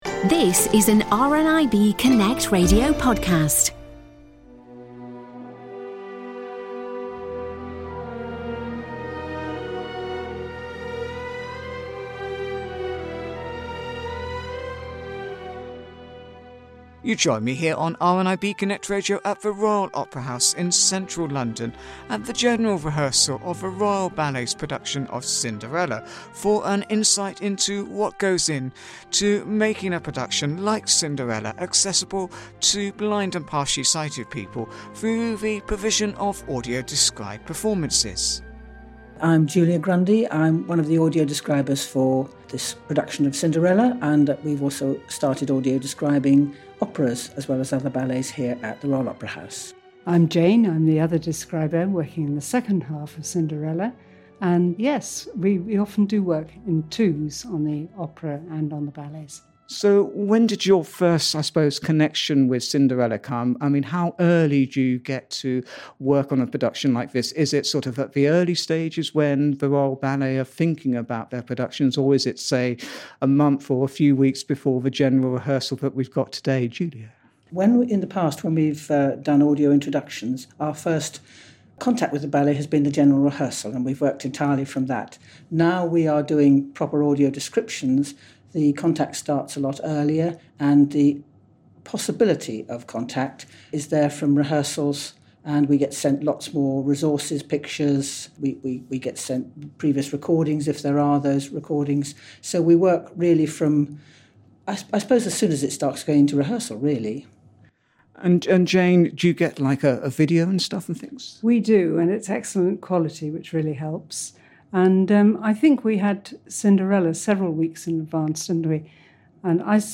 RNIB Connect